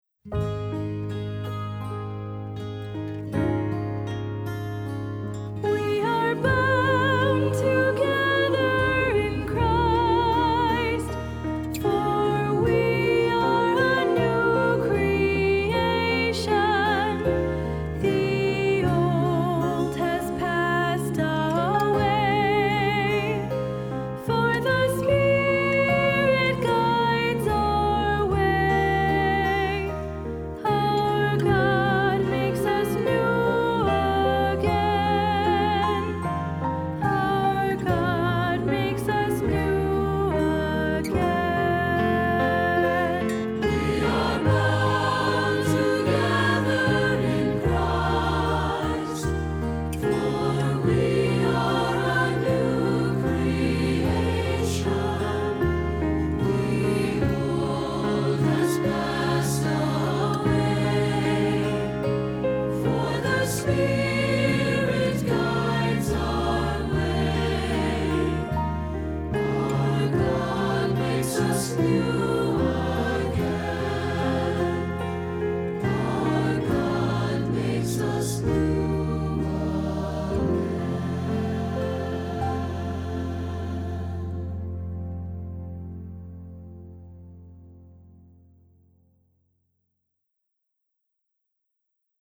Voicing: SAB; SATB; Assembly